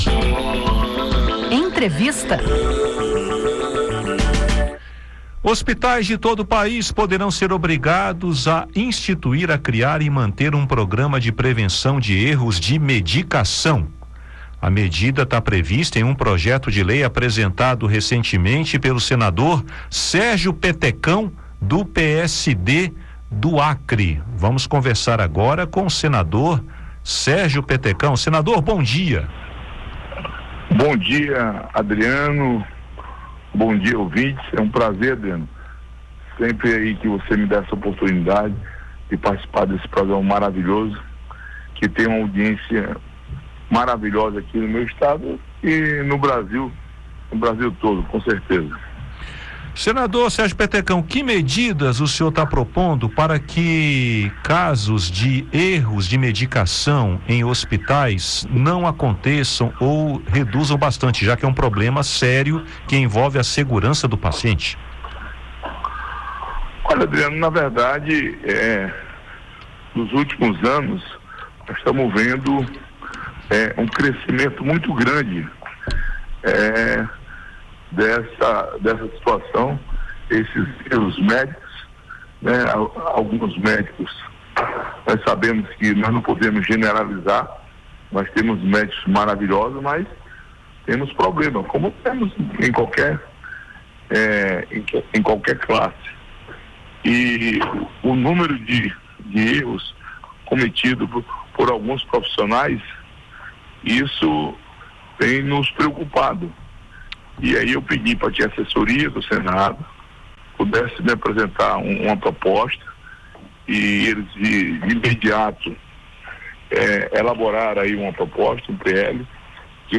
Em entrevista, o senador defende o projeto e destaca a importância do debate nas comissões, com participação dos conselhos federais de medicina e de enfermagem.